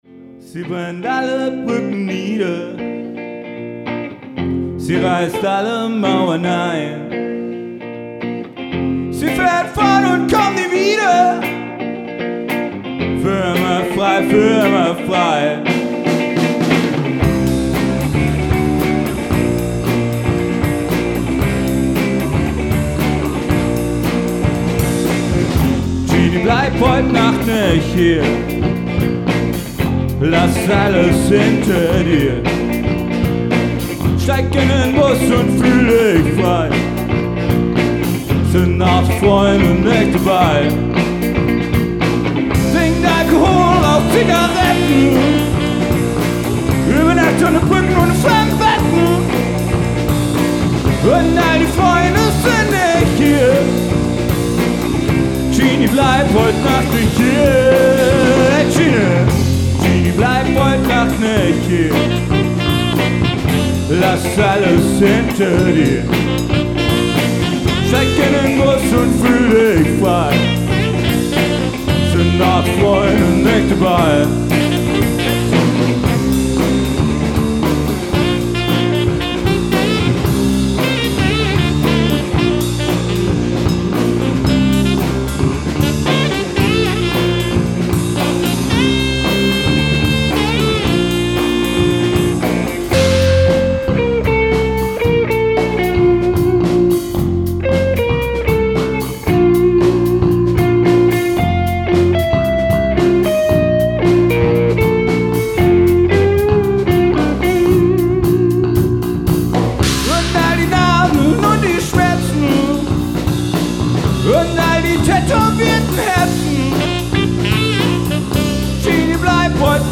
Fröhlicher Rock.
Vocals, Drums, E-Gitarre und grooving Bass.